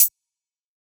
kits/OZ/Closed Hats/Hihat (SummersOver).wav at main
Hihat (SummersOver).wav